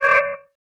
xpanes_steel_bar_door_open.ogg